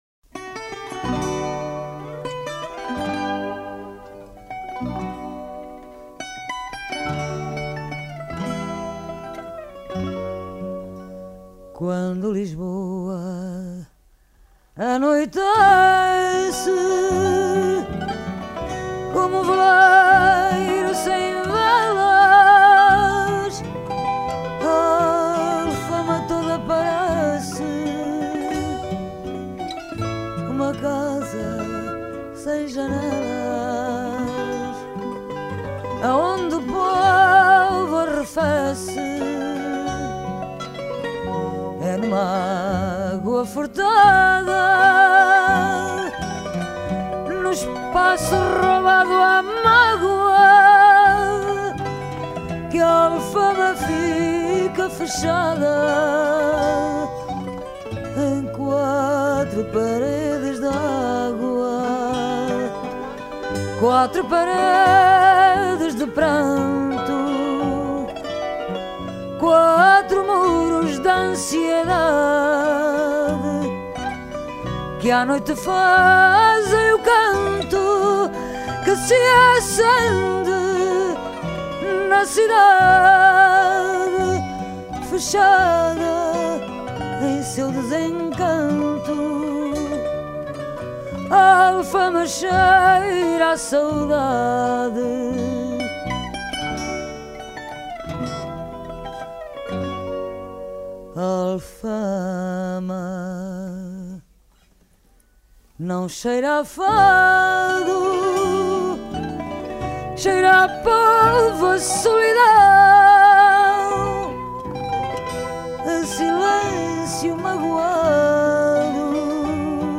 chant
guitare portugaise
basse acoustique.